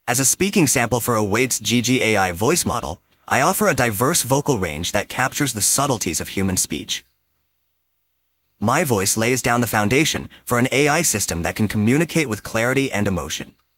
Speaking Voice Of Bob the Builder